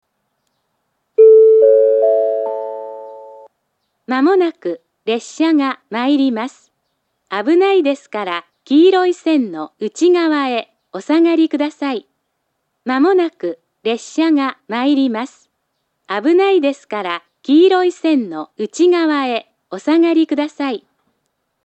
接近放送、発車ベルともにスピーカーは２か所ずつで、列車が止まらないところにも設置されています（ホーム白馬より）。
１番線接近放送 上下とも同じ放送です。